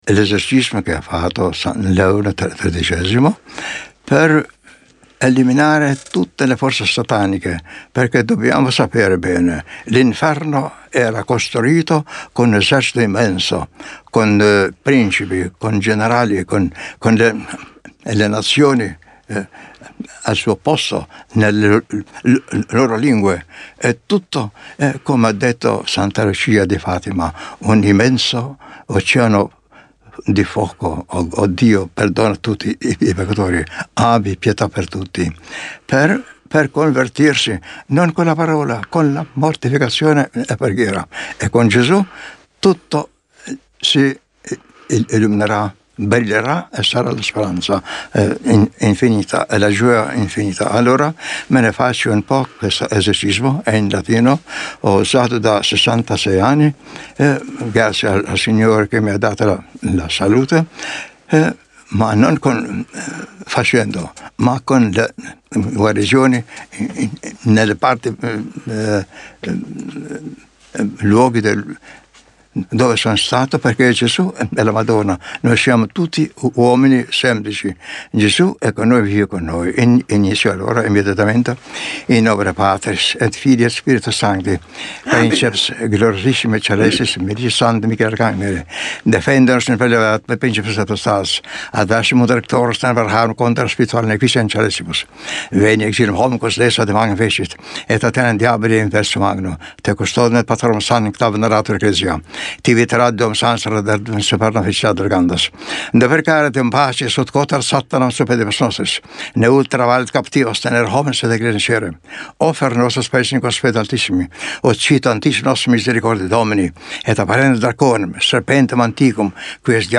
(Esorcismo)
clicca sulla scritta in alto o sulla foto per l'esorcismo del Cardinal Simoni